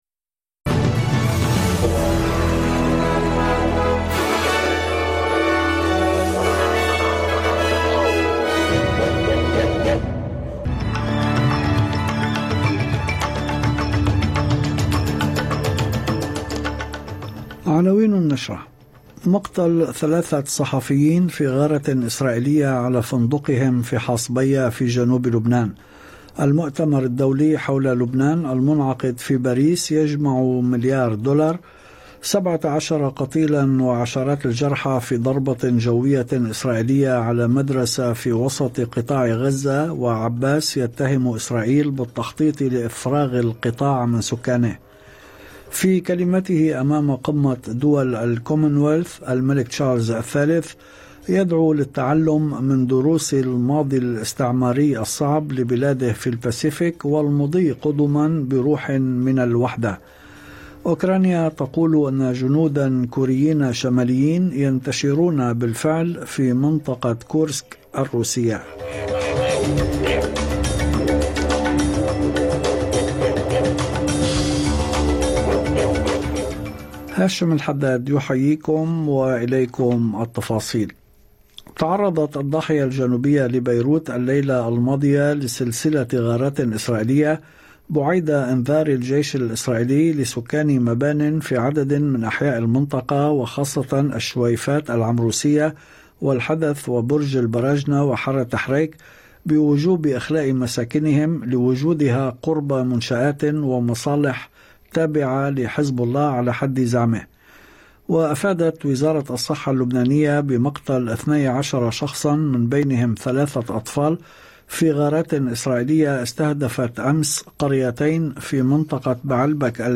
نشرة أخبار المساء 25/10/2024